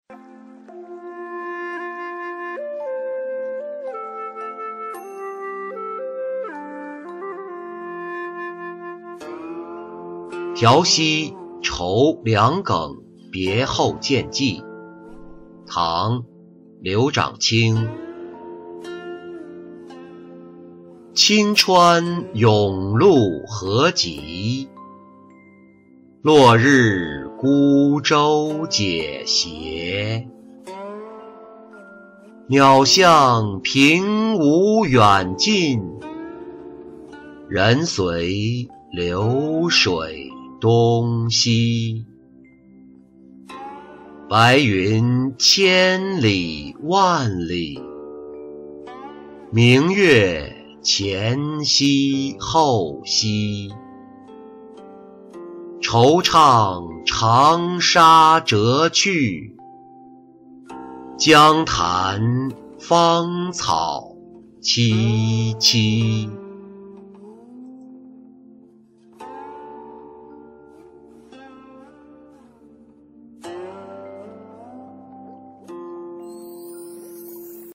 苕溪酬梁耿别后见寄-音频朗读